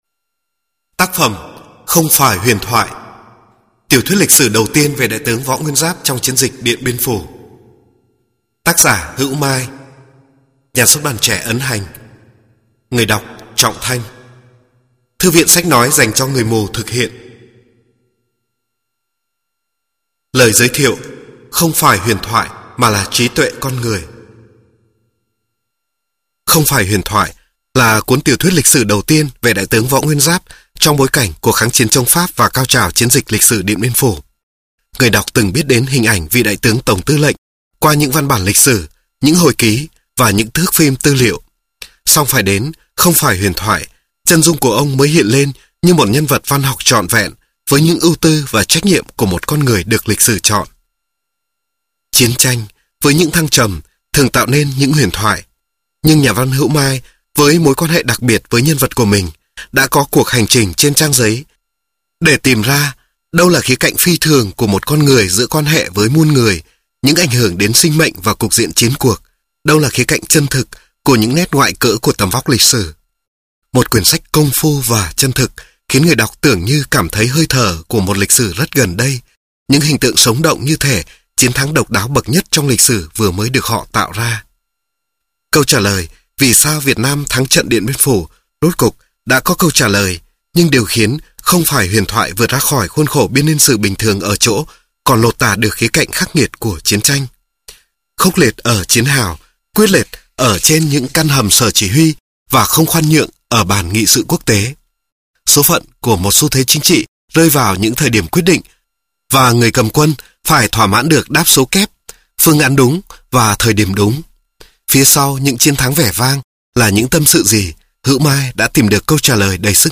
Sách nói Không Phải Huyền Thoại (Chiến Dịch Điện Biên Phủ) - Hữu Mai - Sách Nói Online Hay